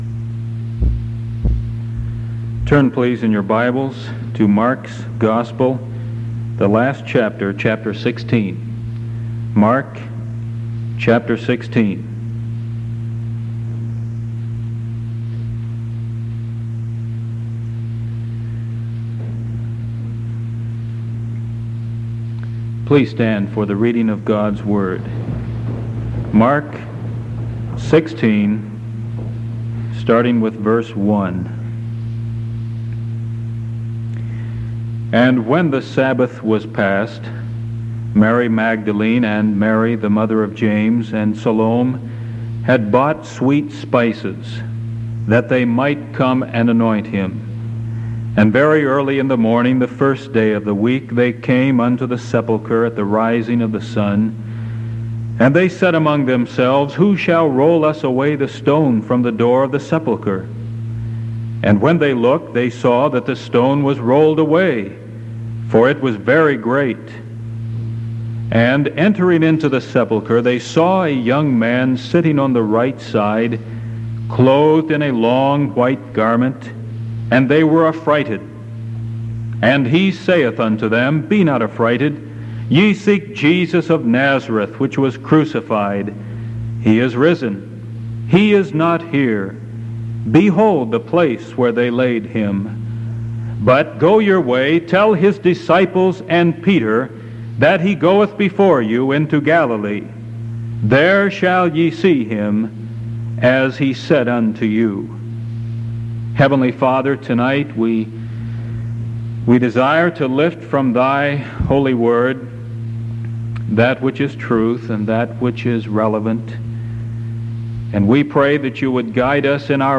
Sermon July 29th 1973 PM